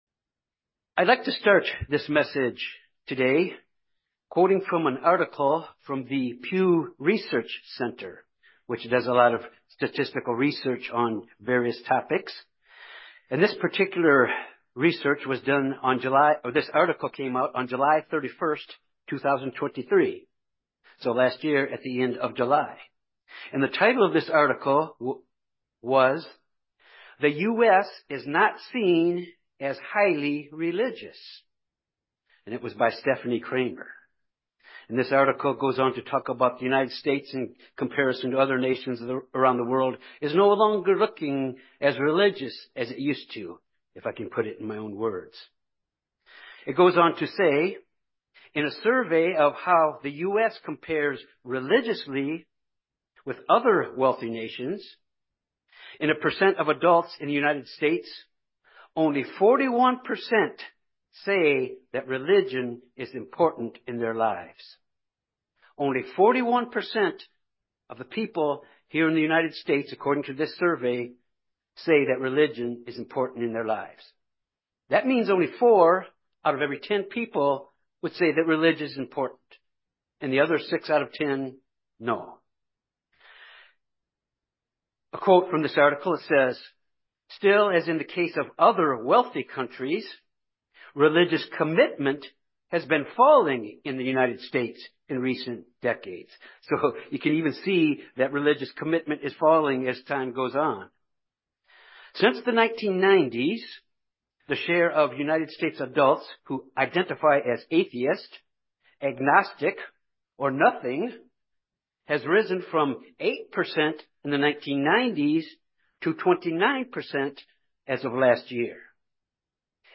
This sermon examines four important aspects of baptism and to commitment to Christ and to move forward to the kingdom of God.